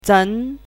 chinese-voice - 汉字语音库
zeng2.mp3